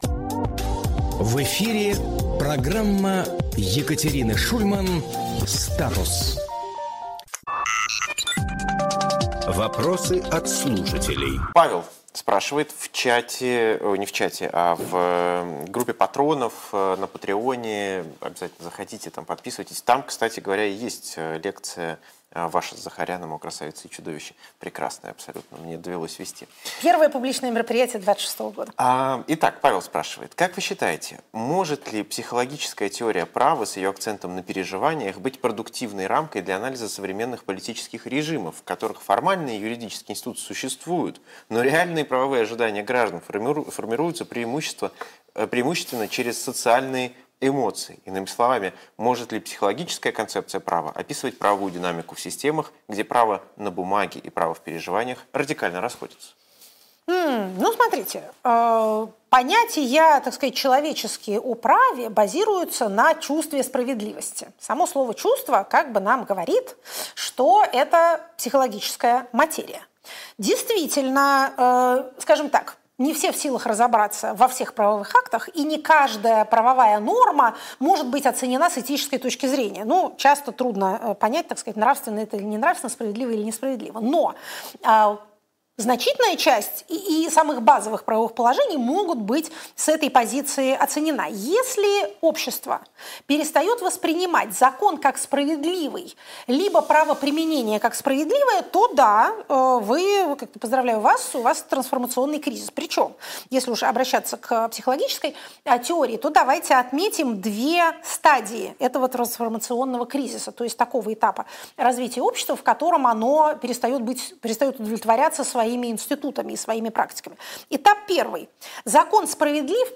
Максим Курниковглавный редактор «Эха», журналист
Екатерина Шульманполитолог
Фрагмент эфира от 13.01.2026